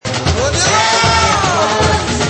バックの歌は置いておいて、男性の台詞のようなものは、どのように聞こえますか？